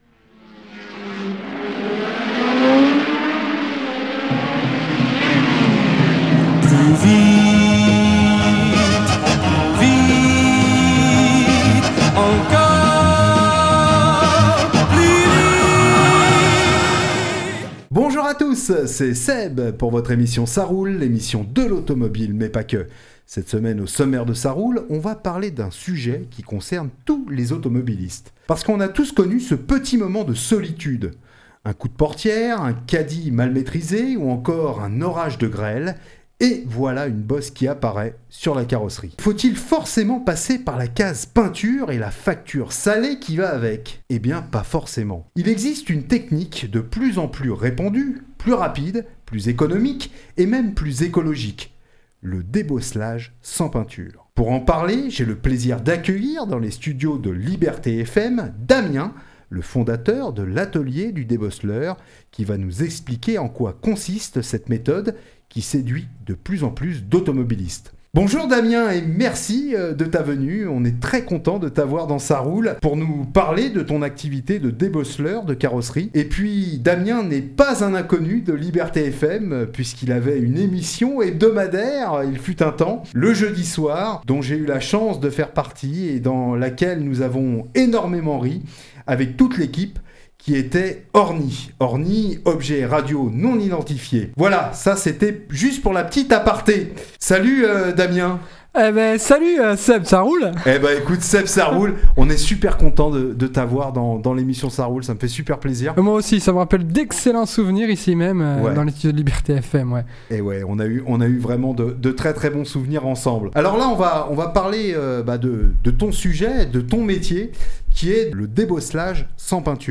Il existe une technique de plus en plus répandue, plus rapide, plus économique et même plus écologique : le débosselage sans peinture. Pour en parler, j’ai le plaisir d’accueillir dans les studios de Liberté FM